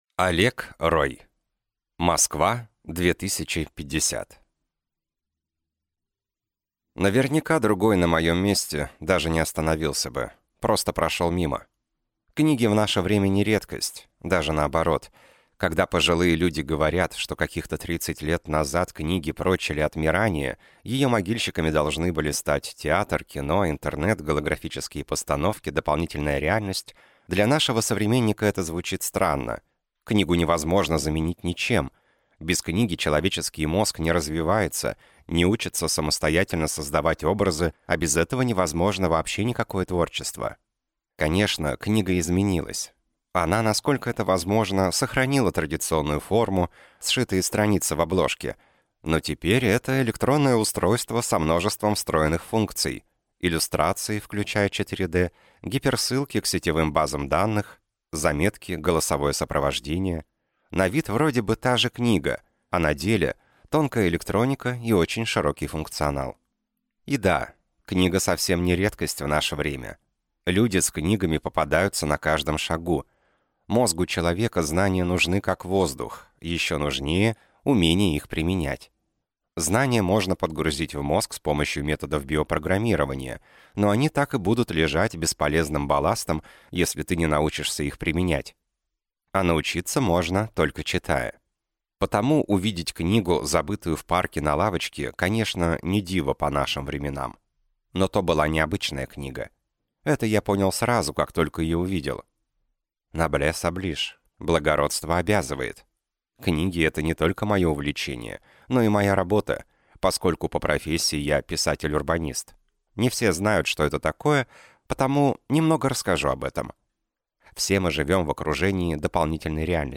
Аудиокнига Москва 2050 | Библиотека аудиокниг